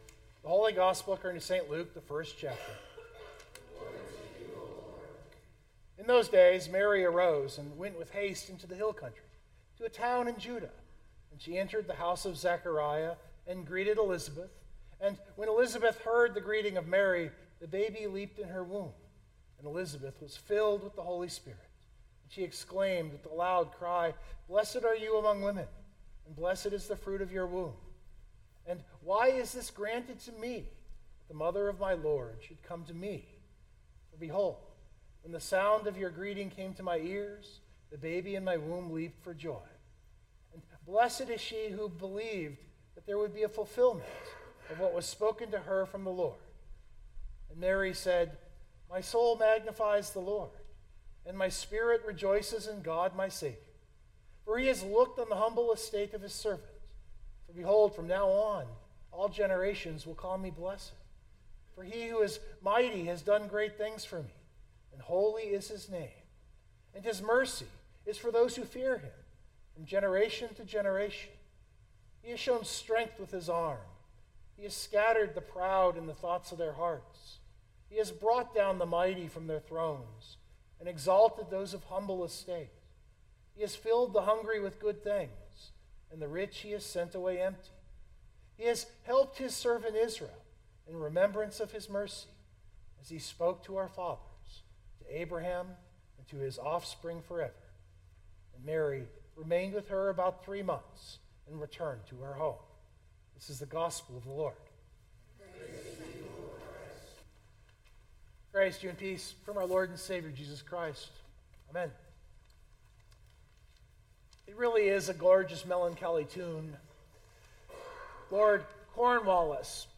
That’s the opening story of this sermon. The biblical text is Mary’s Magnificat.